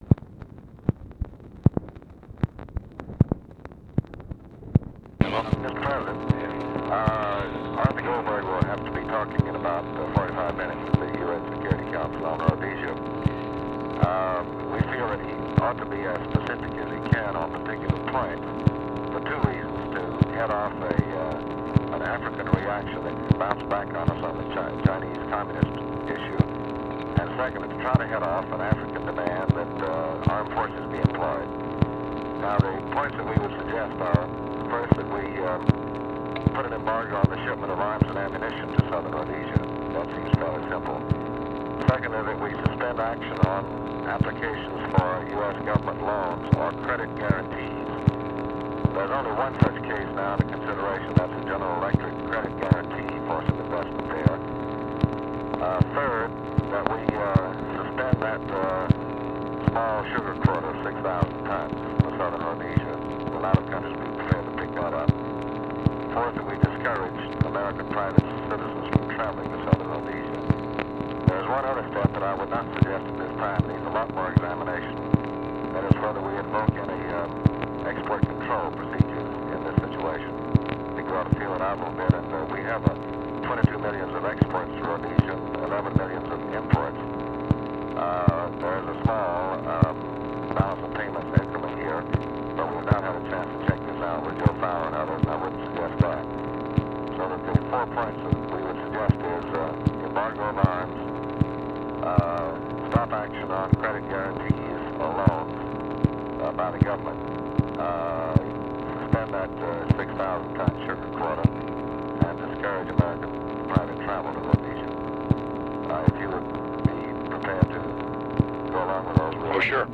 Conversation with DEAN RUSK, November 12, 1965